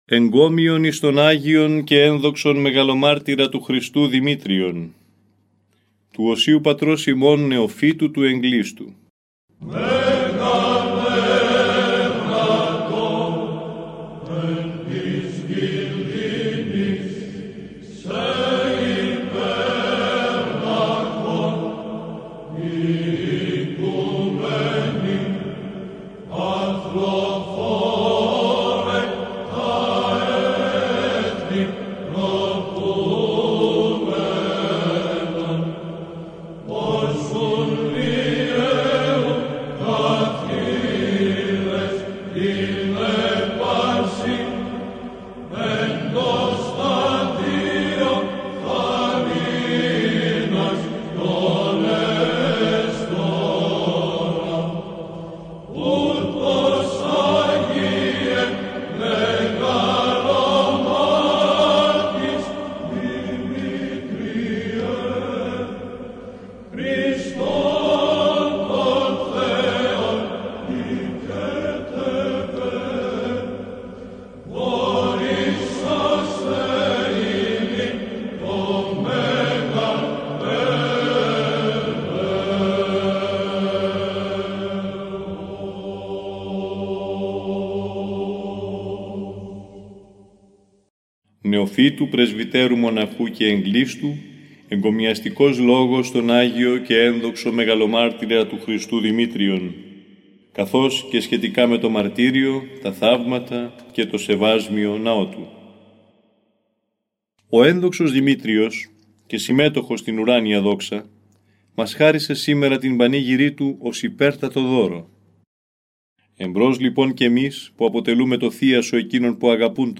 Ακούστε τον επόμενο πατερικό εγκωμιαστικό λόγο, όπως αυτός “δημοσιεύθηκε” στο 149-ο τεύχος (Σεπτεμβρίου – Οκτωβρίου του 2014) του ηχητικού περιοδικού μας, Ορθόδοξη Πορεία.